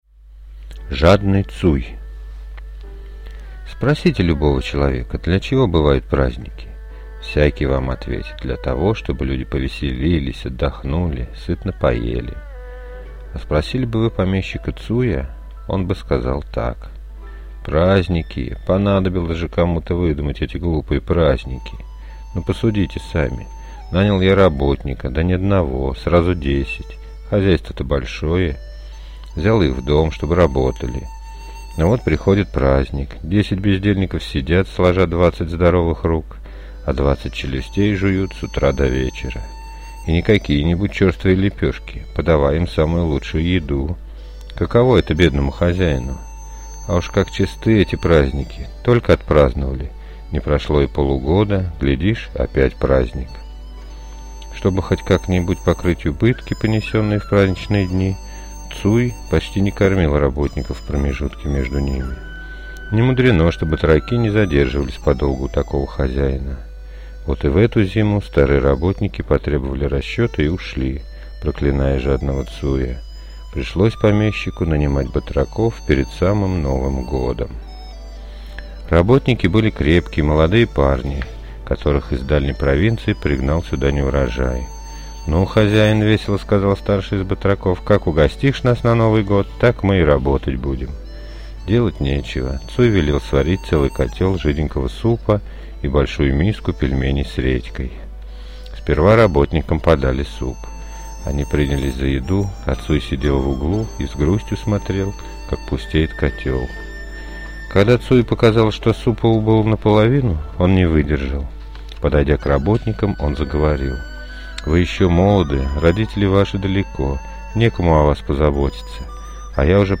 Жадный Цуй – китайская аудиосказка